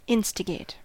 Ääntäminen
Ääntäminen US : IPA : [ˈɪnµ(ùtµ)ù.stə.ˌɡeɪt]